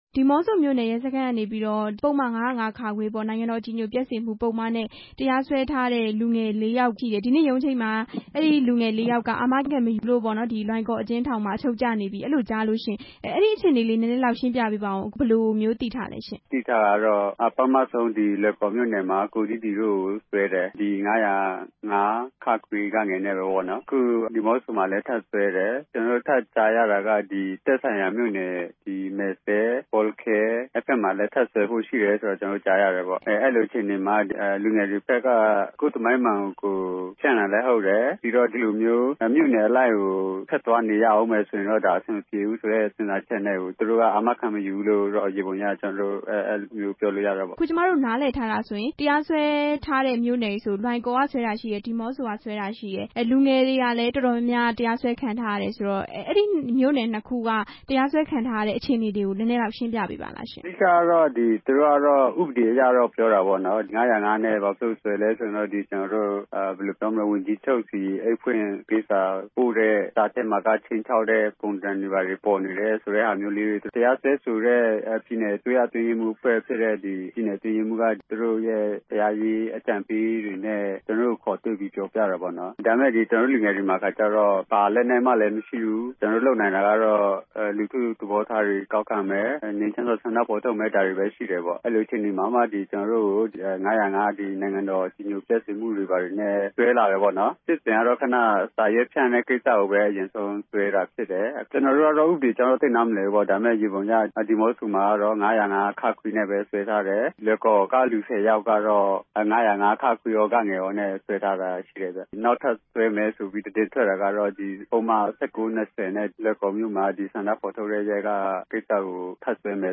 မေးမြန်းခန်း